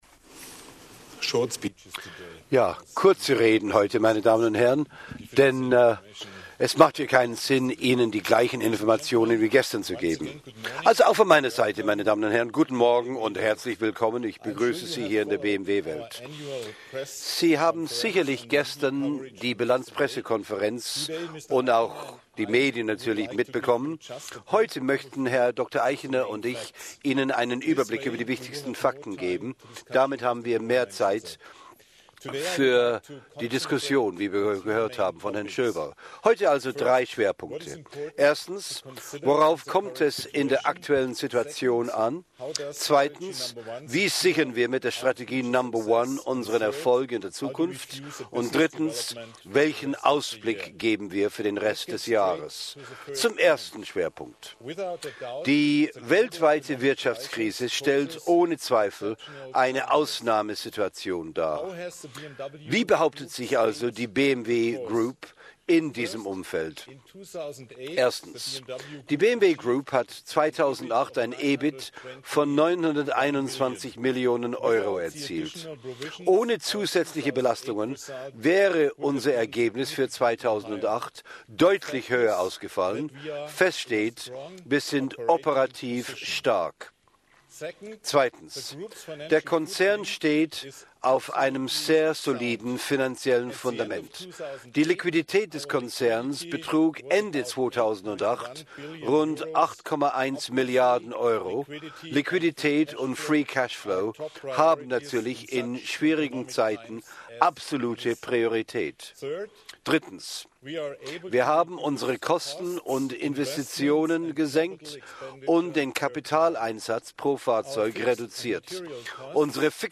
Speech Dr. -Ing. Norbert Reithofer.